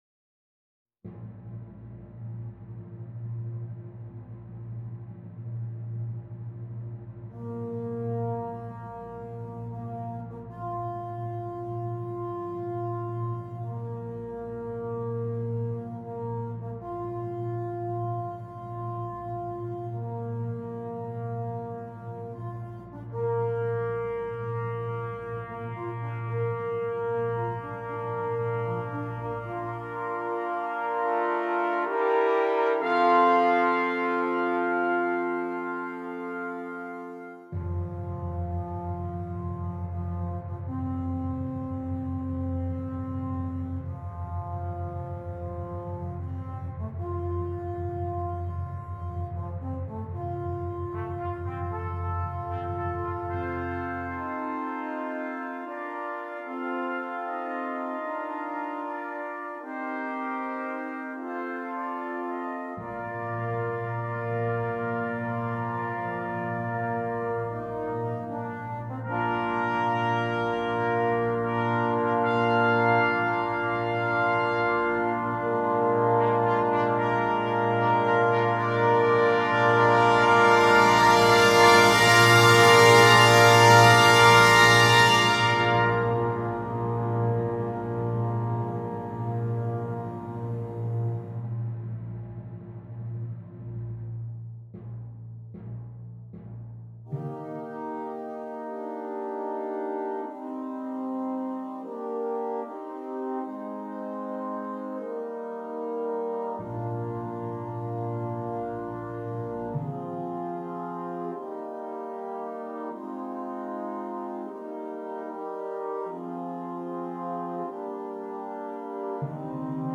9 Trumpets and optional Timpani